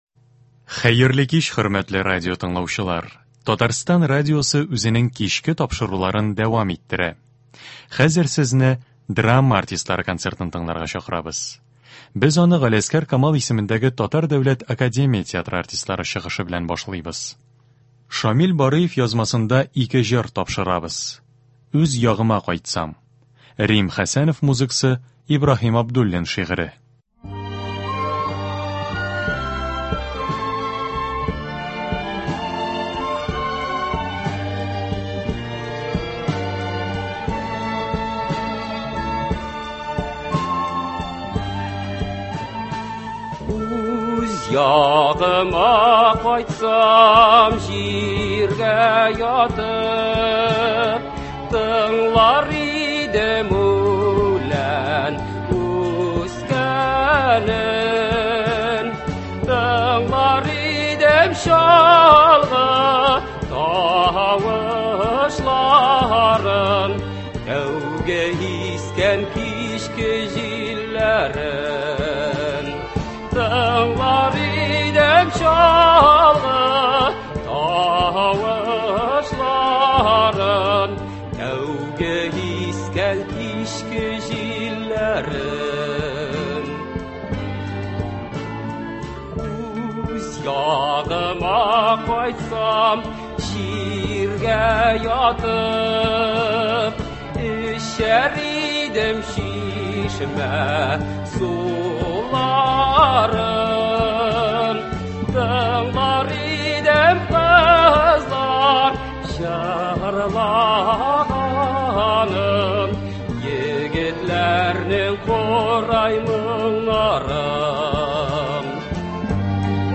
Драма артистлары концерты.
Концерт. 4 март.